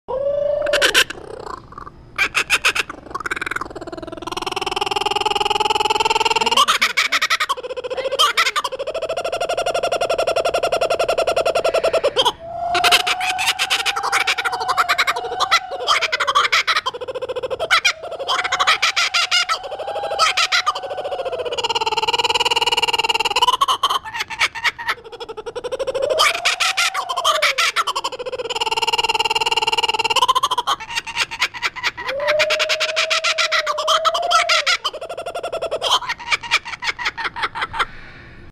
Martin-chasseur géant (Laughing Kookaburra)
Oiseau mythique dans la culture aborigène d’Australie, on ne peut pas manquer son chant, ou plutôt son rire rauque, lors des balades en forêt.
a-kookaburra-call-or-laugh.mp3